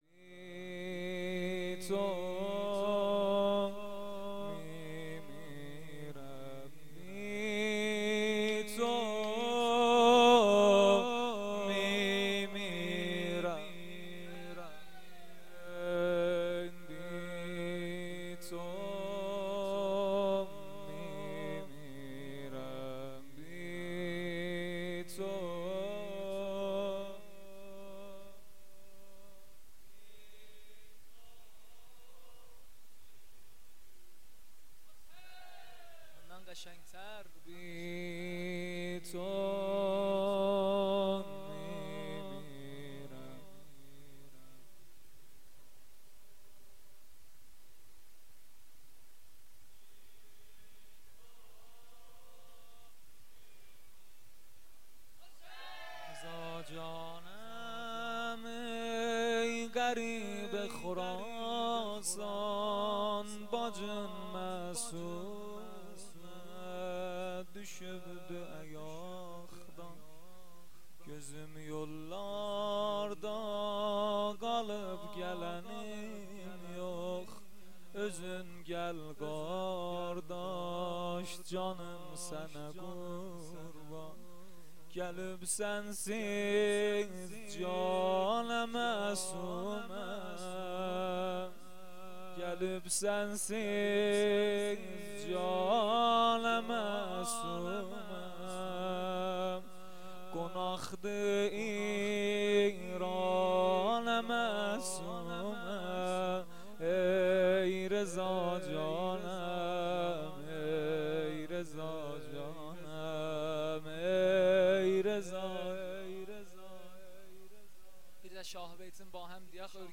مراسم هفتگی | 24 آبان ماه 1400